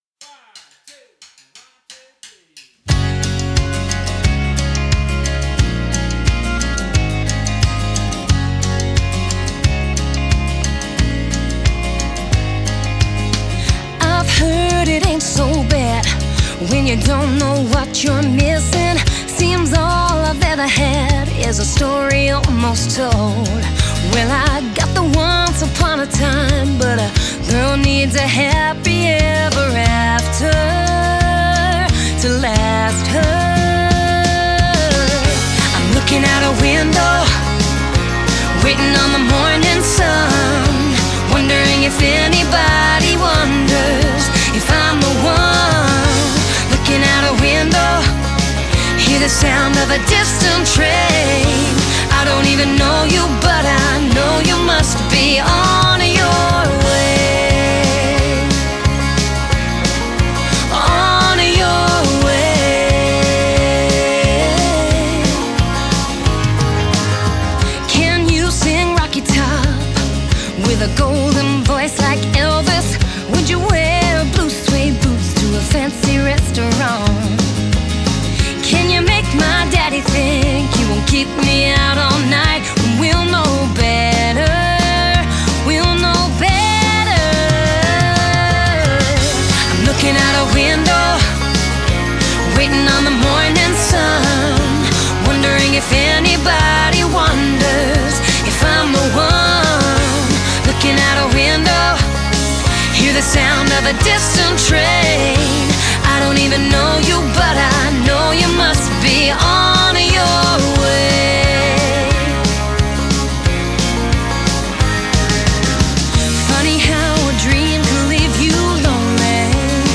Genre: Country, Folk, Female Vocalist